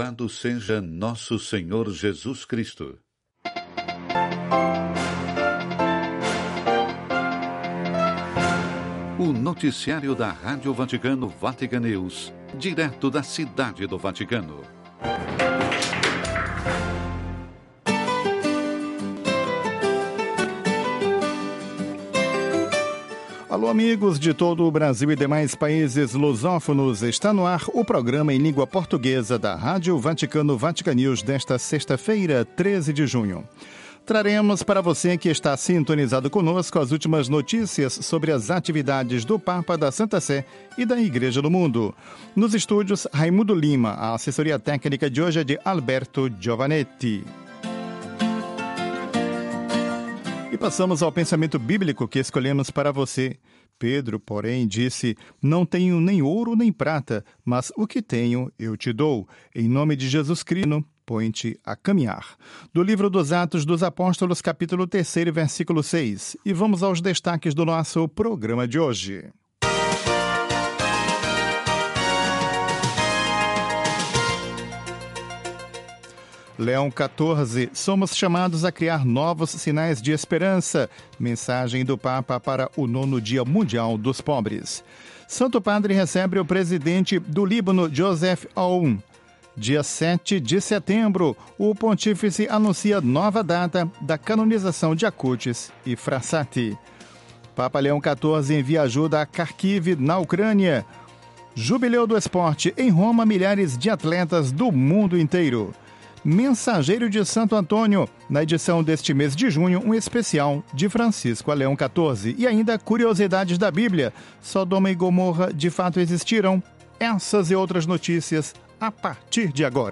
Noticiário (17:00 CET).